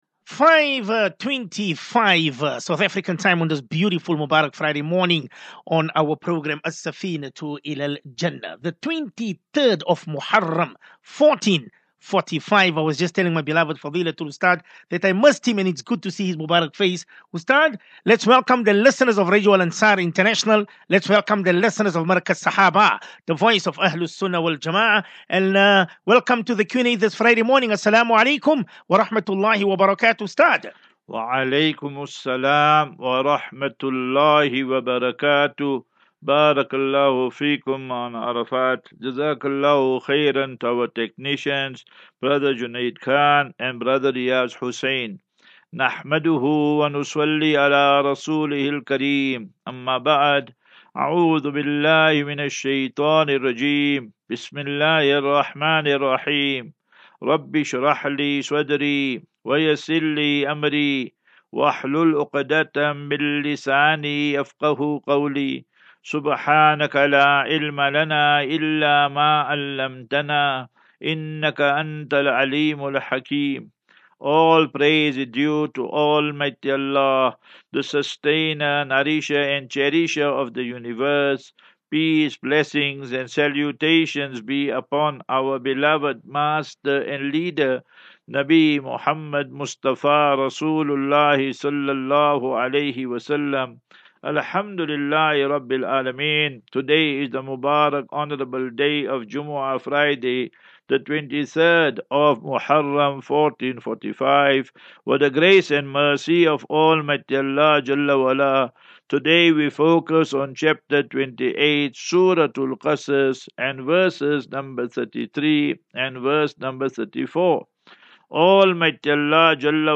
As Safinatu Ilal Jannah Naseeha and Q and A 11 Aug 11 August 2023.